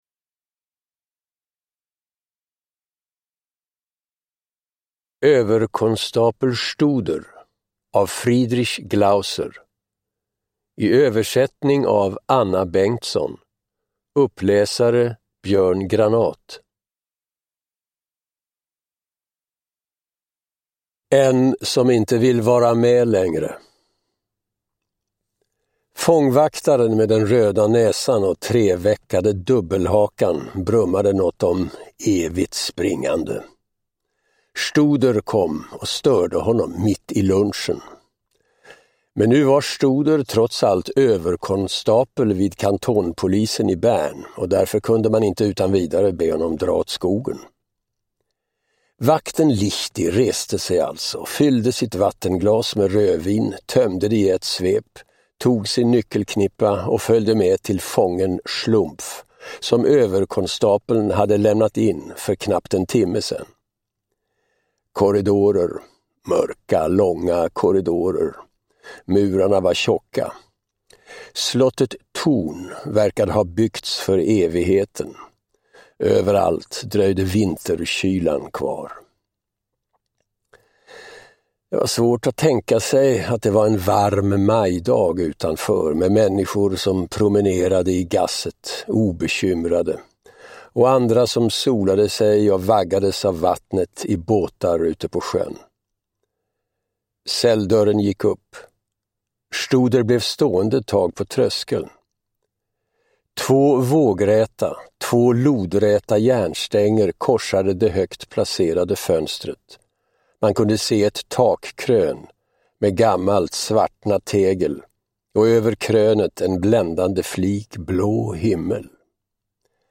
Uppläsare: Björn Granath